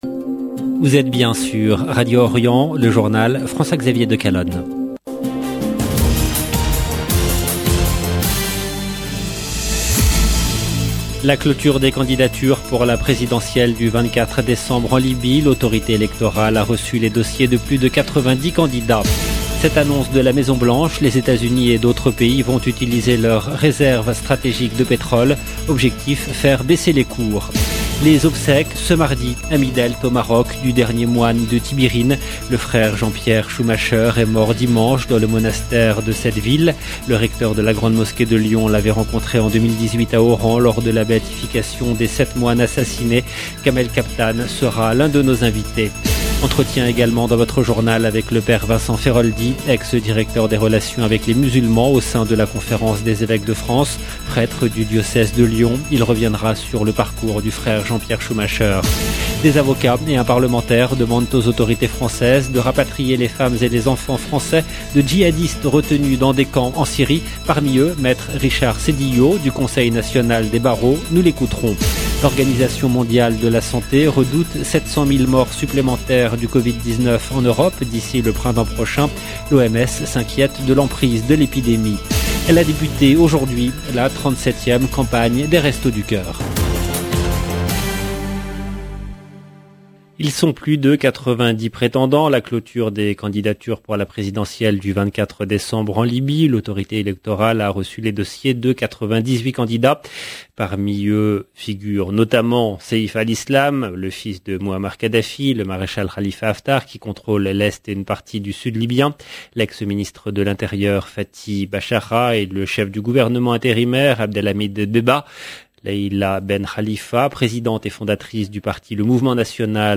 LE JOURNAL DU SOIR EN LANGUE FRANÇAISE DU 23/11/21 LB JOURNAL EN LANGUE FRANÇAISE